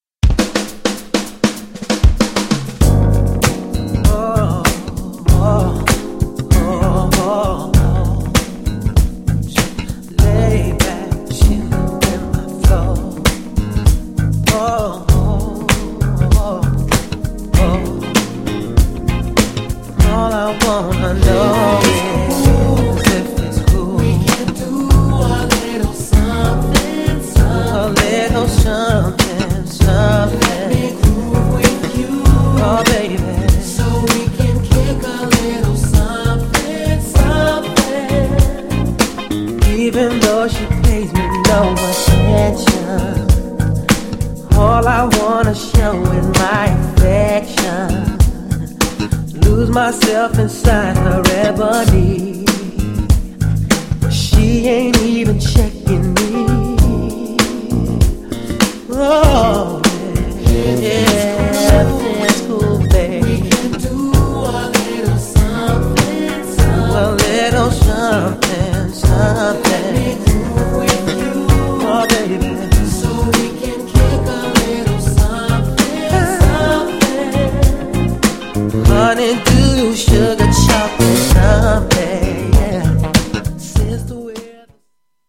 GENRE R&B
BPM 101〜105BPM
# JAZZY